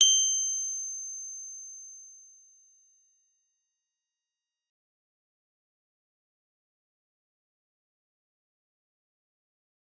G_Musicbox-G7-f.wav